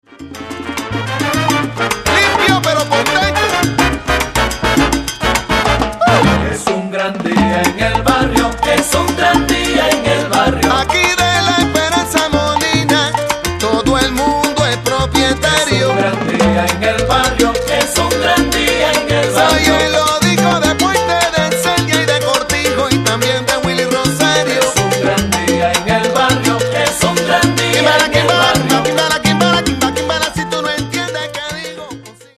Salsa dura actuelle :